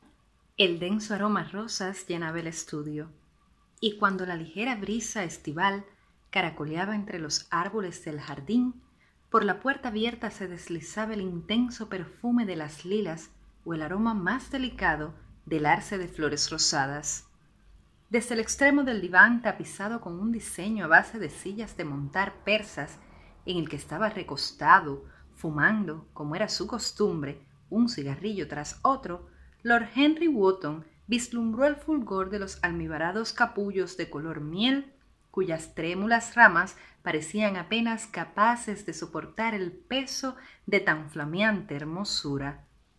Locutora y narradora Voice over
Sprechprobe: Industrie (Muttersprache):